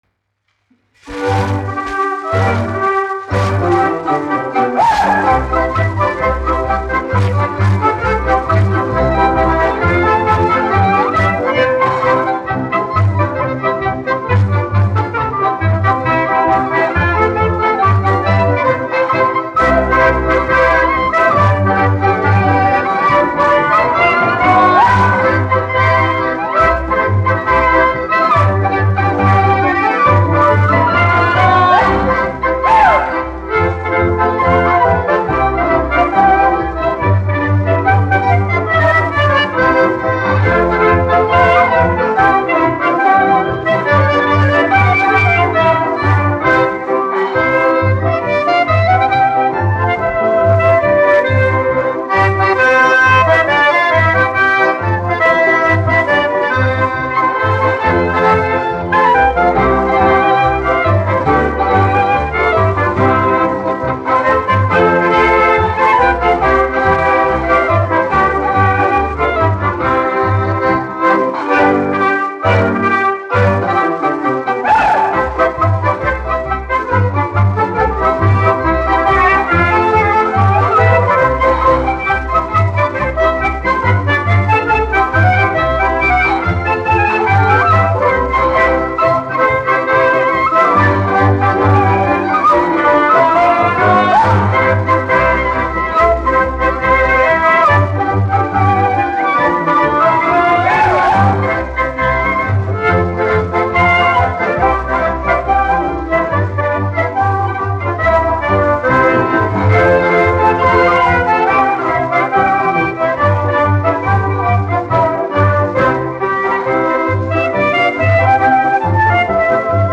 1 skpl. : analogs, 78 apgr/min, mono ; 25 cm
Polkas
Populārā instrumentālā mūzika
Skaņuplate
Latvijas vēsturiskie šellaka skaņuplašu ieraksti (Kolekcija)